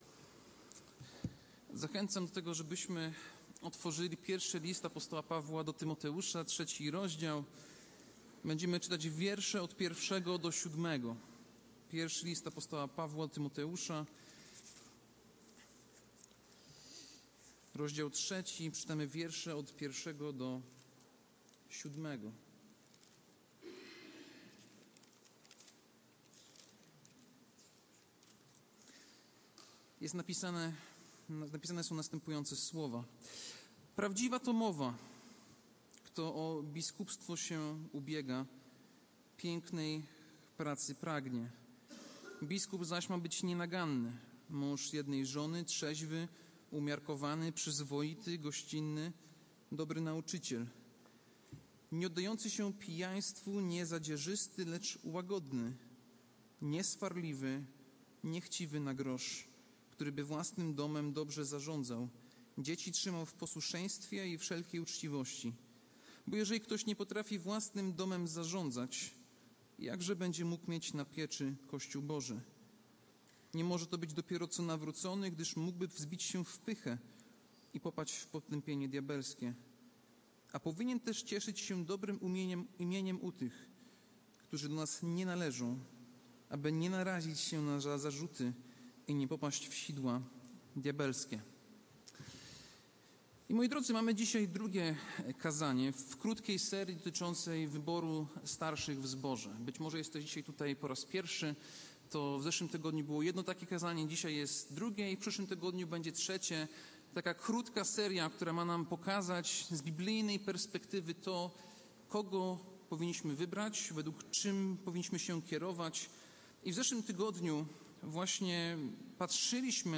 Kaznodzieja